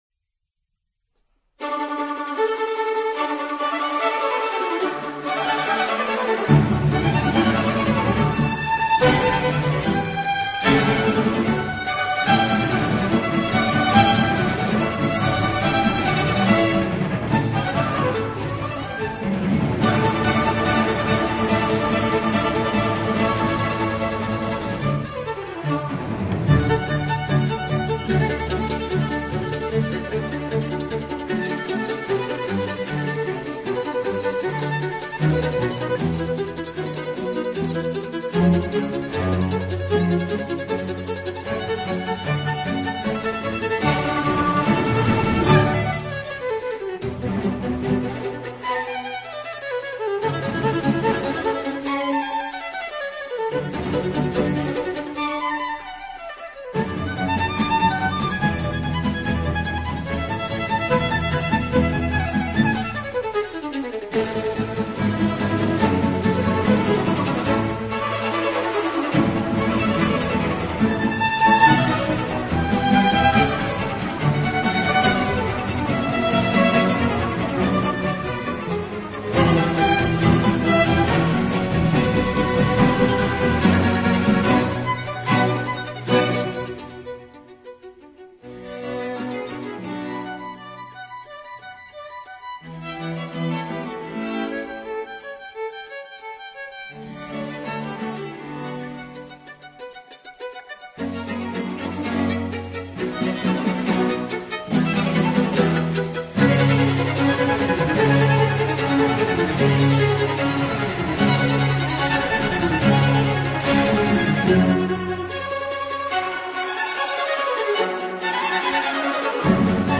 Orchestre de chambre